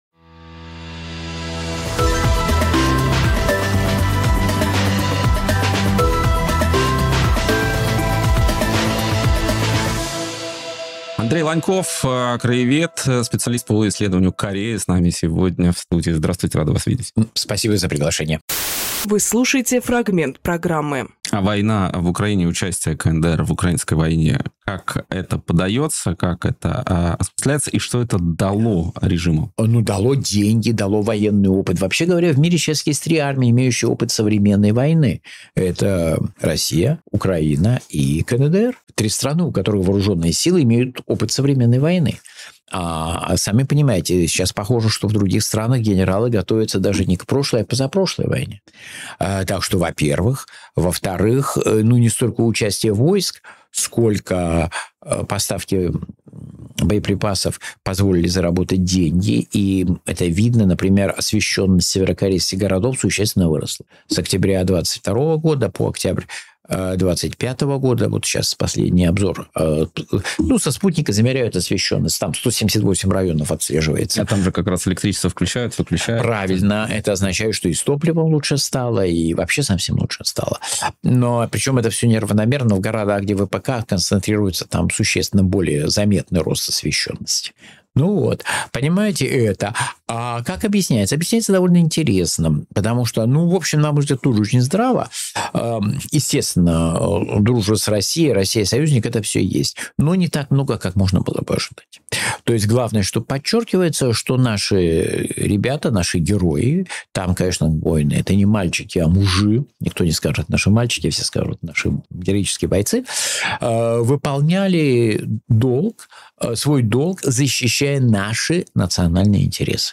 Фрагмент эфира от 24.02.26